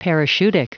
Prononciation du mot parachutic en anglais (fichier audio)
Prononciation du mot : parachutic